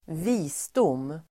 Ladda ner uttalet
Uttal: [²v'i:sdom:]